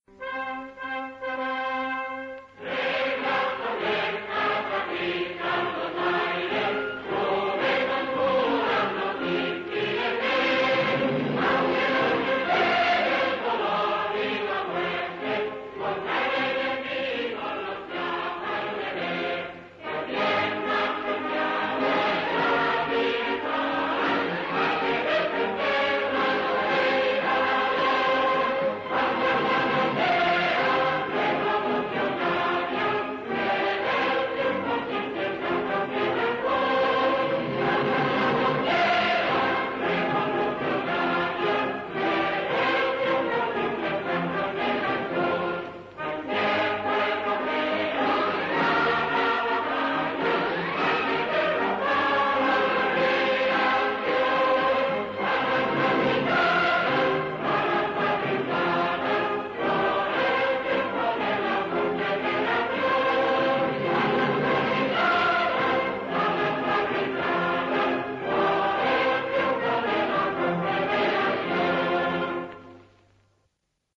HIMNO ANARQUISTA